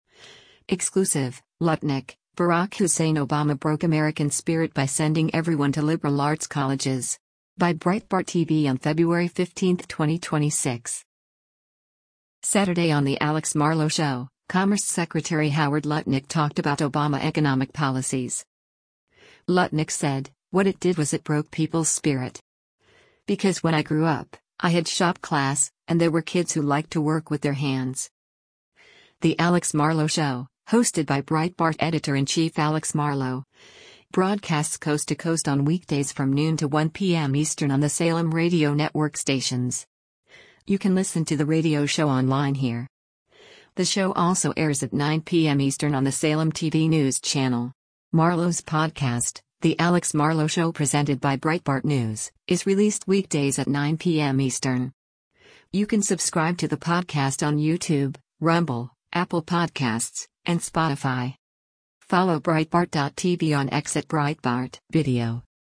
Saturday on “The Alex Marlow Show,” Commerce Secretary Howard Lutnick talked about Obama economic policies.